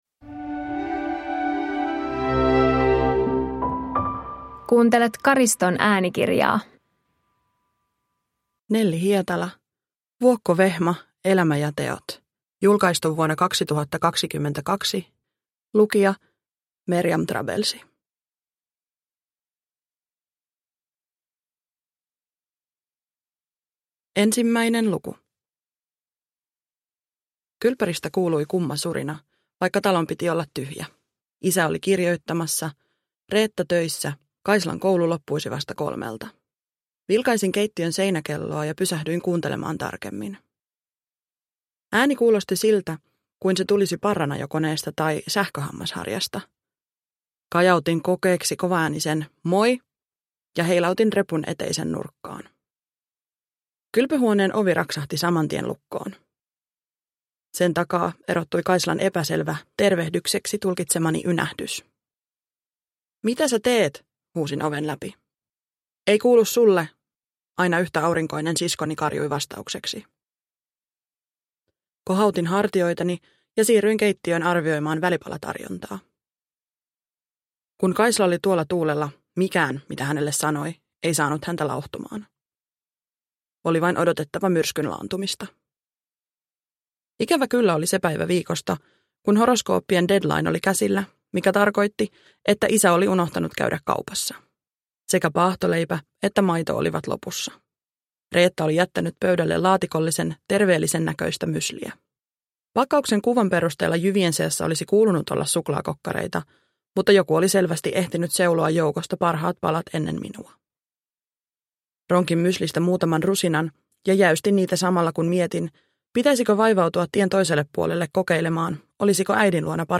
Vuokko Vehma - elämä ja teot – Ljudbok